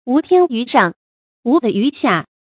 注音：ㄨˊ ㄊㄧㄢ ㄧㄩˊ ㄕㄤˋ ，ㄨˊ ㄉㄧˋ ㄧㄩˊ ㄒㄧㄚˋ